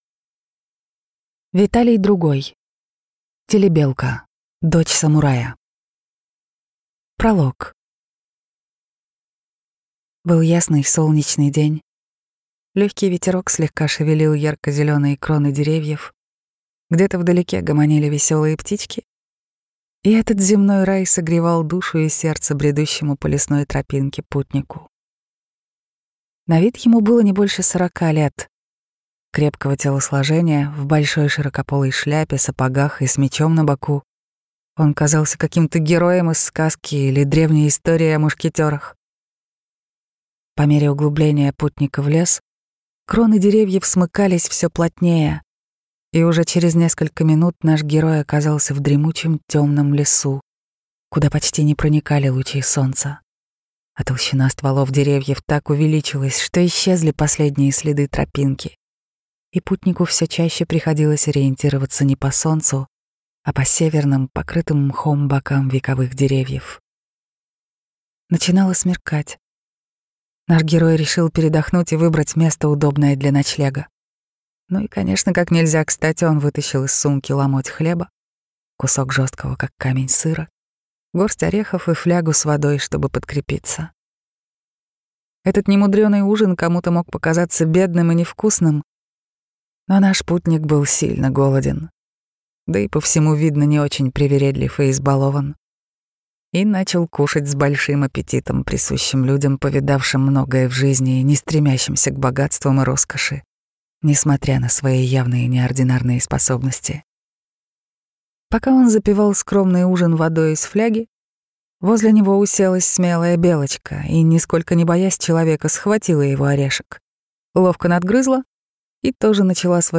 Аудиокнига Телебелка – дочь самурая | Библиотека аудиокниг